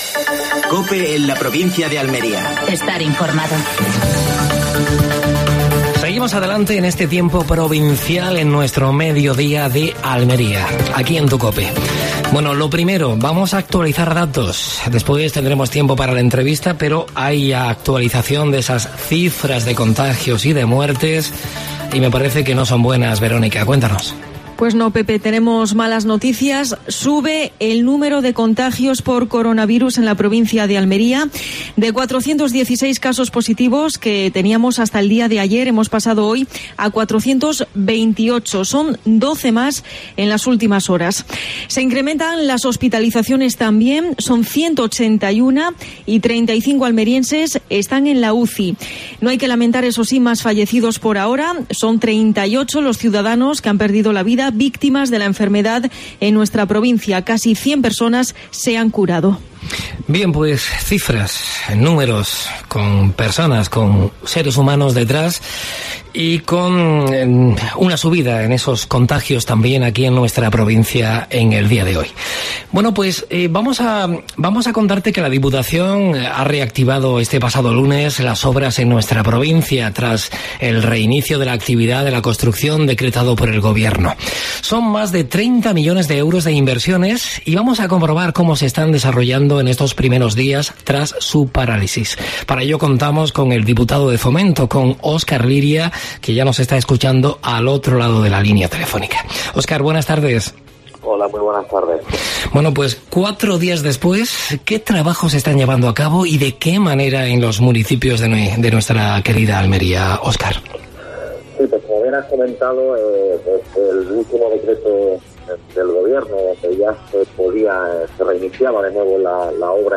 Datos actualizados por el coronavirus en la provincia. Entrevista a Óscar Liria (diputado de Fomento de la Diputación Provincial).